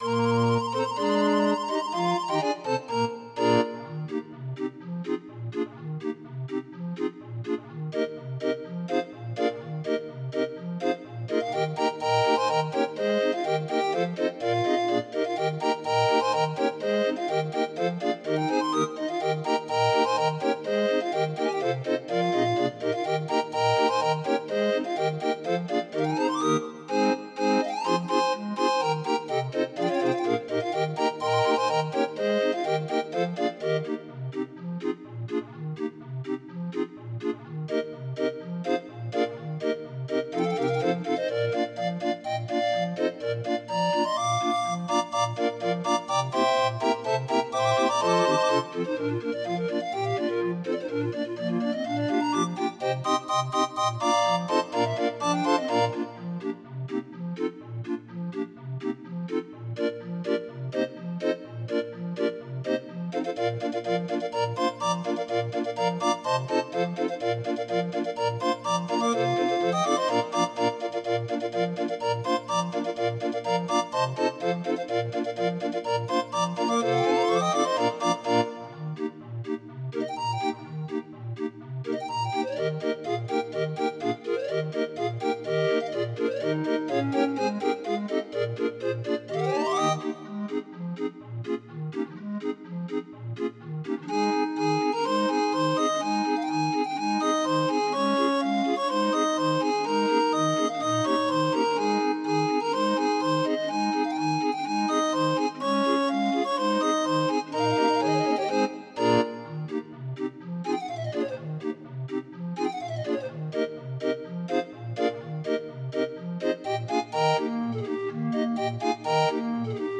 Demo of 31 note MIDI file